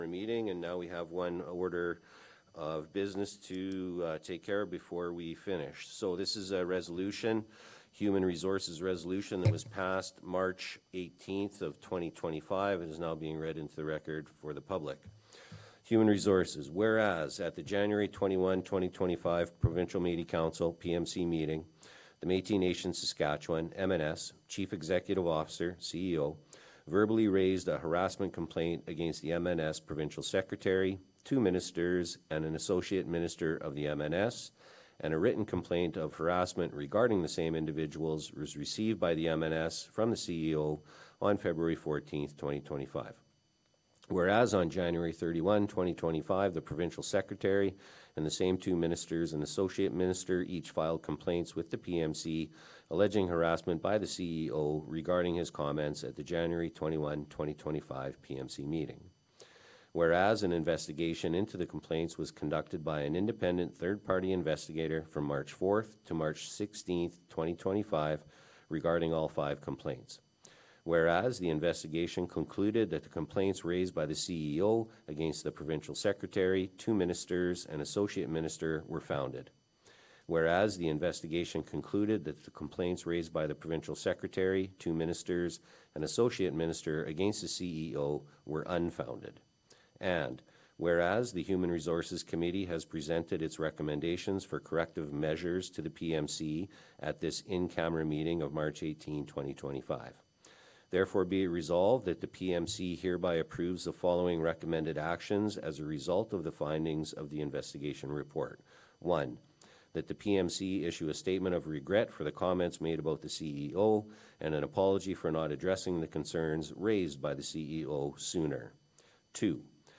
In response, the resolution that was passed in the March meeting to remove the four women from attending Provincial Metis Council meetings was read into record at the June 25 PMC Meeting.
The full reading of the resolution is available here:
PMC-Resolution-on-Ministers-Removal.mp3